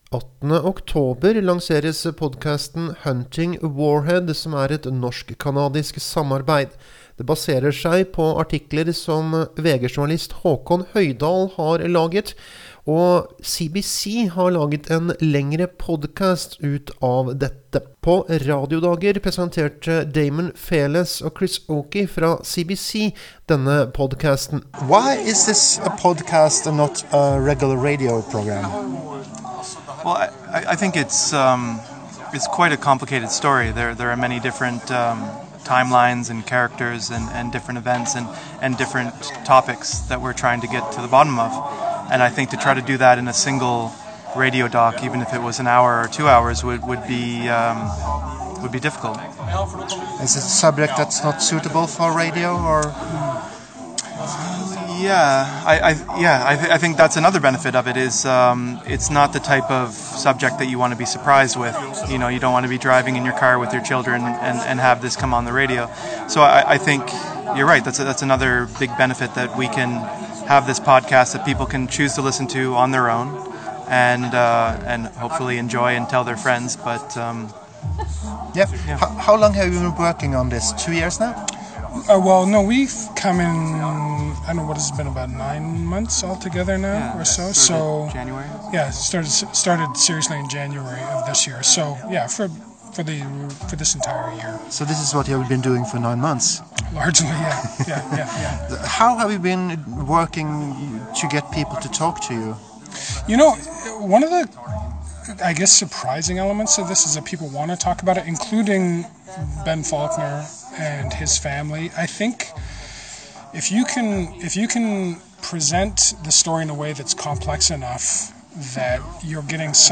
Intervju
Tatt opp på Radiodager